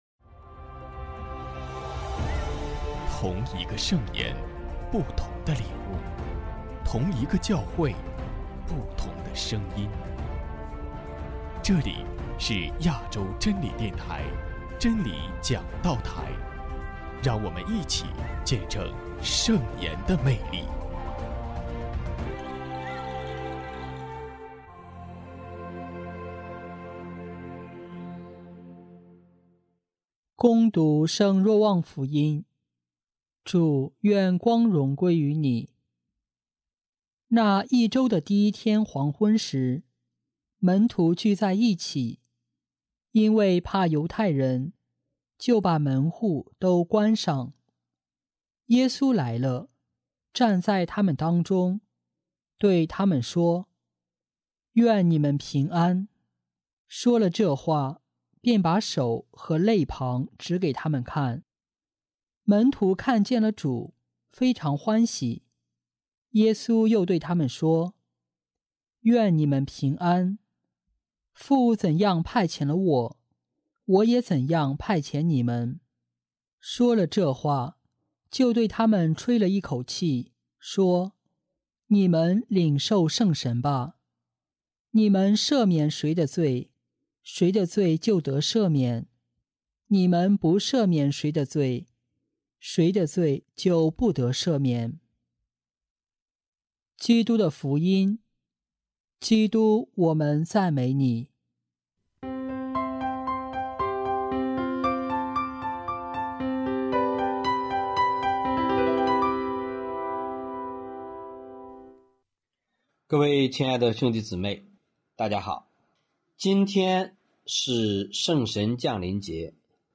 ——基督的福音 证道 主题： 在智慧之爱中合而为一 各位亲爱的兄弟姊妹： 大家好!